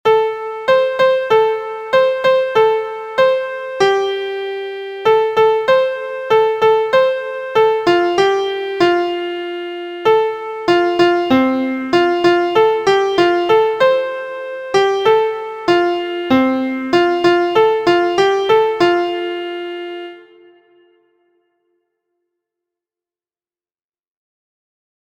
• Origin: USA – Folk Song
• Key: F Major
• Time: 4/4
• Form: ABCD
• Pitches: intermediate: So Do Re Mi So
• Musical Elements: notes: half, dotted quarter, quarter, eighth